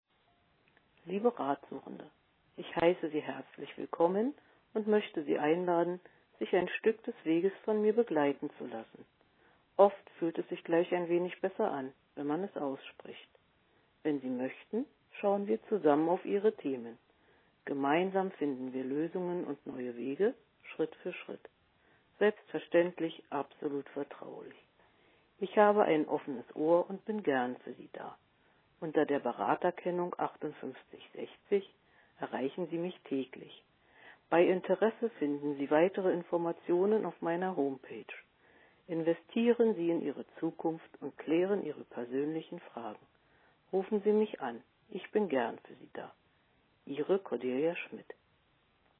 Audioportrait